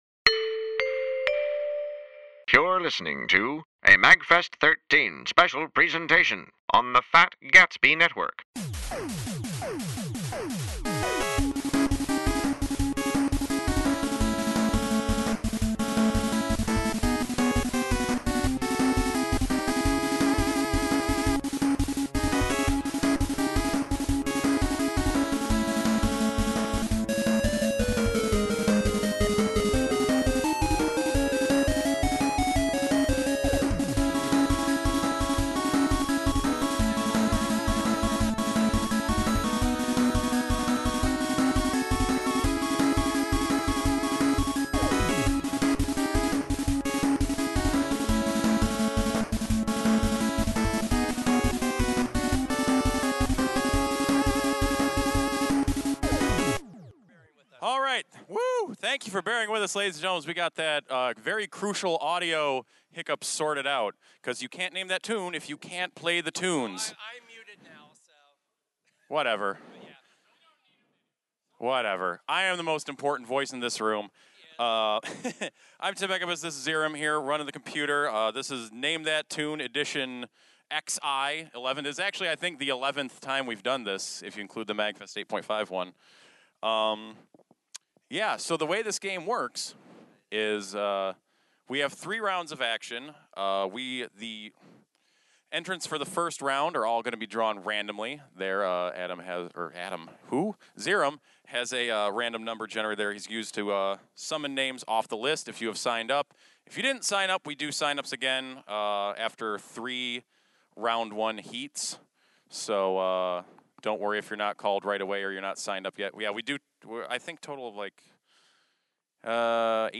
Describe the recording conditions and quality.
MAGFest13-NameThatTuneXI.mp3